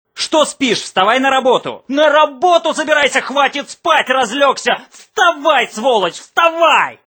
Мр3 Будильники